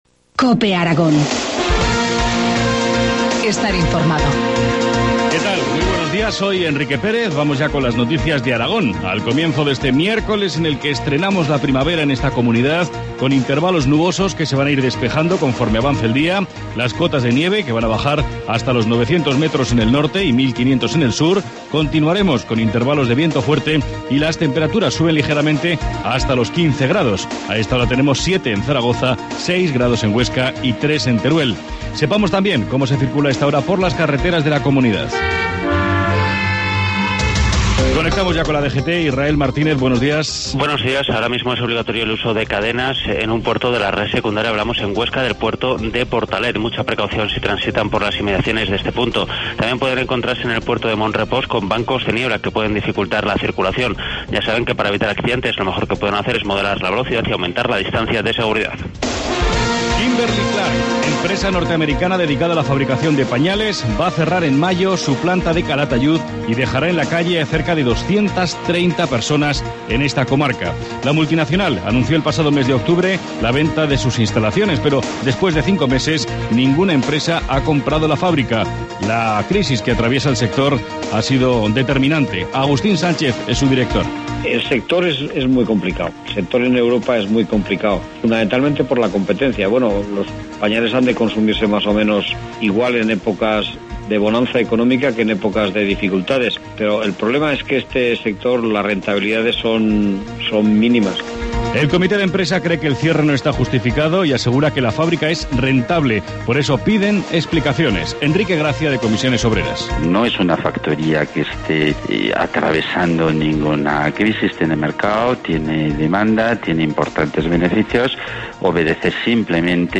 Informativo matinal, miércoles 20 de marzo, 7.25 horas